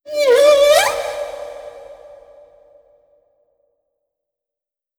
khloCritter_Male32-Verb.wav